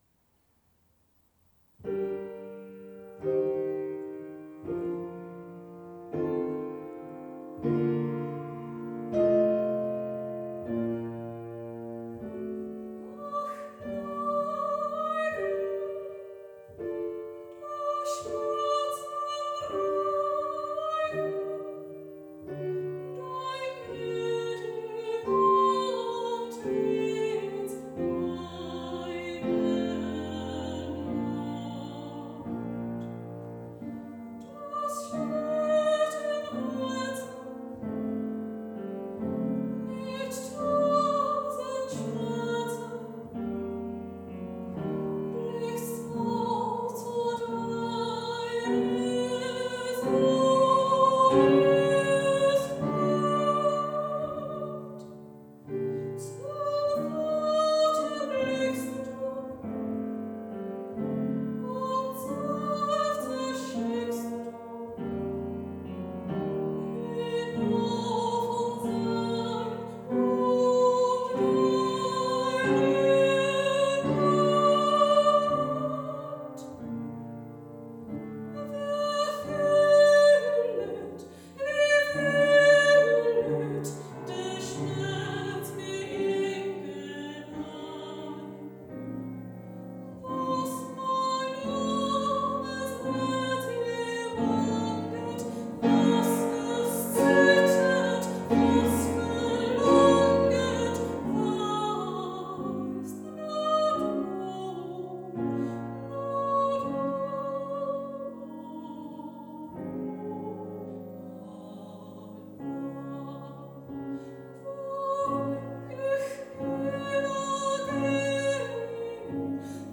Johann Valentin Meder (1649 - 1719): Unser keiner lebt ihm selber. Nahrávka ze závěrečného koncertu Convivia 2014.
Sopránové sólo
Housle
violoncello
varhany
kontrabas